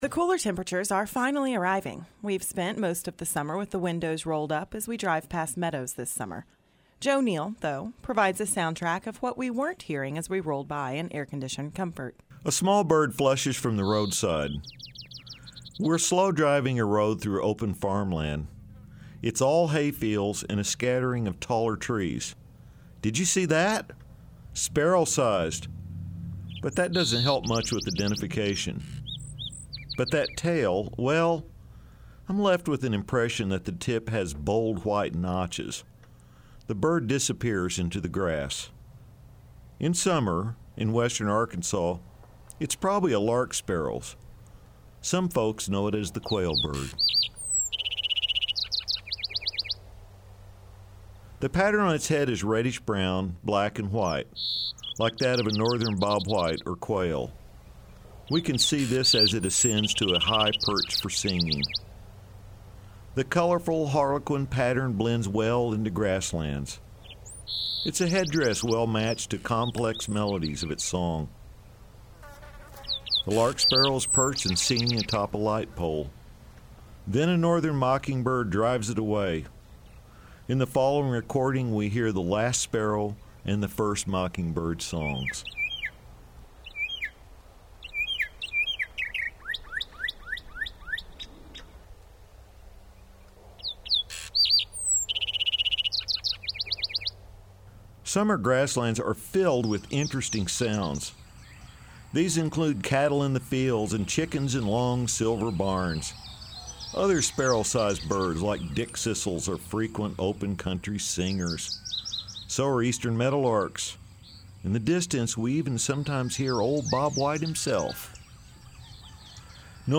Prime Time for Meadow Listening